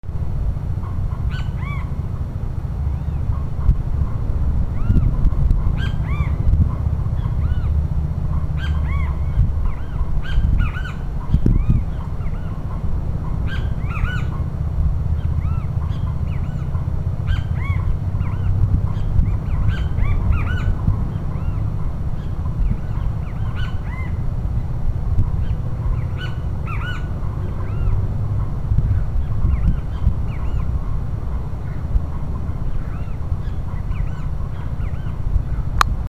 De smaal is in staat een heel scala aan denigrerende klanken te produceren. Als ze met meer zijn lijkt er soms een strijd uit te barsten om wie er het smaalst kan fluiten.